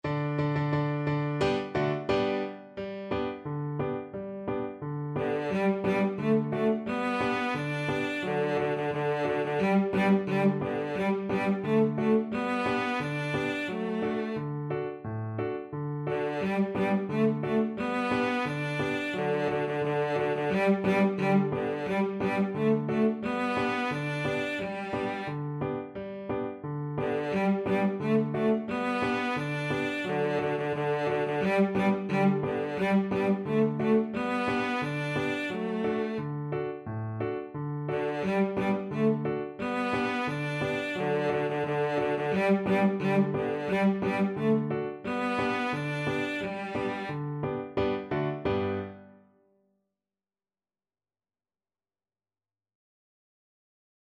Traditional Music of unknown author.
Steady march =c.88
2/4 (View more 2/4 Music)